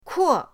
kuo4.mp3